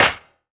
• Clap One Shot D Key 06.wav
Royality free clap one shot - kick tuned to the D note. Loudest frequency: 2517Hz
clap-one-shot-d-key-06-ZVp.wav